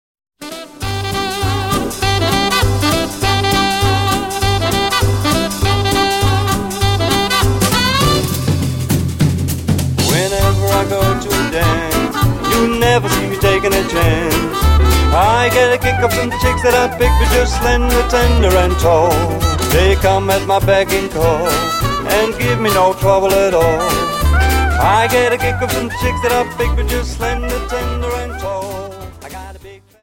Dance: Quickstep Song